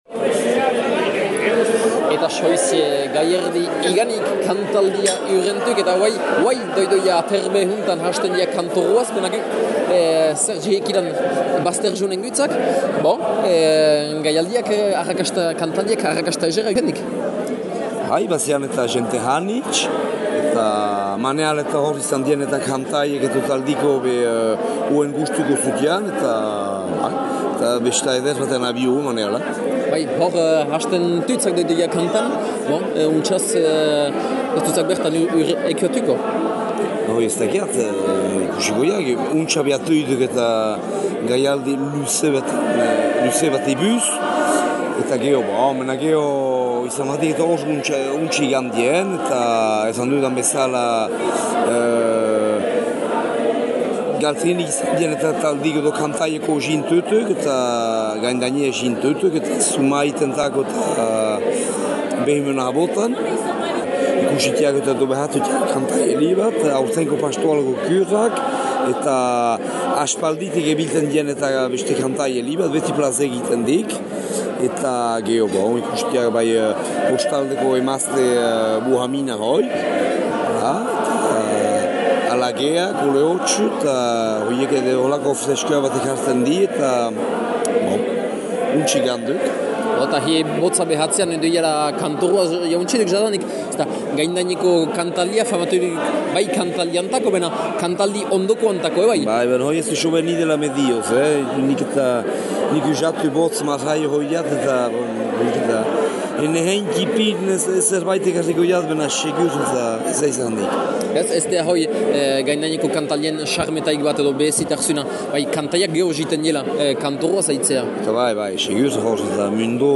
Ondotik ostatüan ere bazen giro; harat eramaiten zütüegü herritar, kantari, eta kantazaleen arteala: